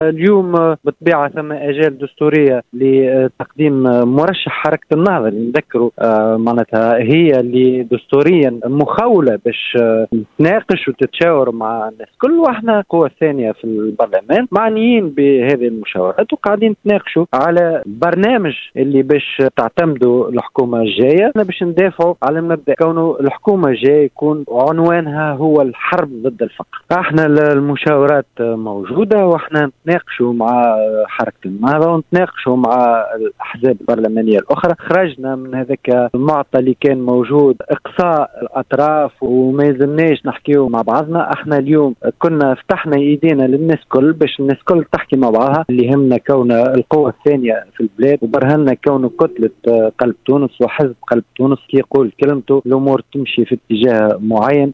M. Khelifi a précisé, dans une déclaration accordée à Mosaïque FM, que ces concertations porteront essentiellement sur le programme gouvernemental primant l’éradication de la pauvreté.